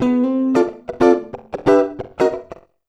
92FUNKY  8.wav